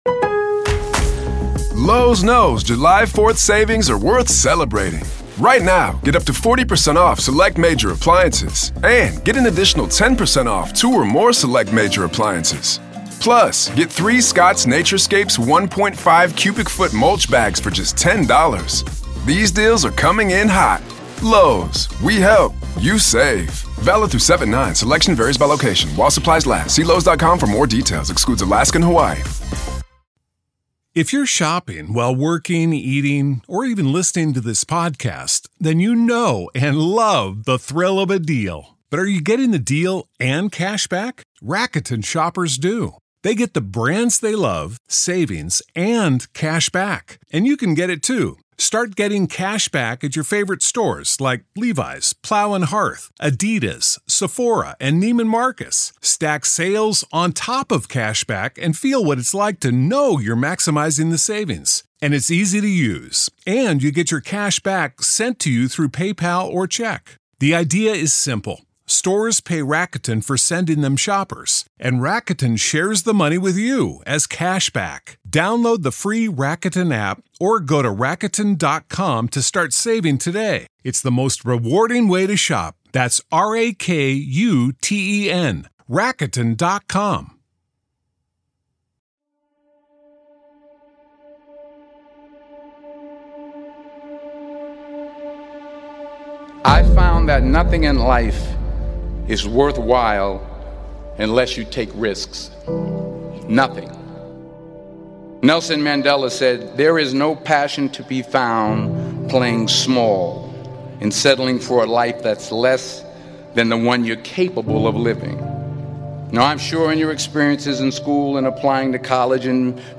Cultivating Resilience through Life's Challenges - Motivational Speech